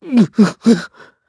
KaselB-Vox_Sad_kr_b.wav